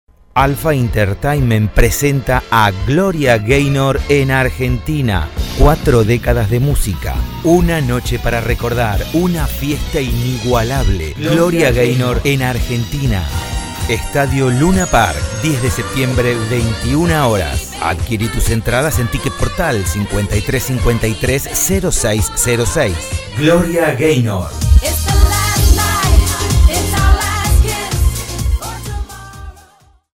Masculino
PROMO GLORIA GAYNOR